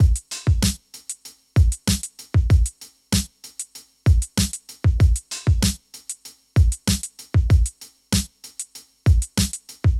DRUMS 1.wav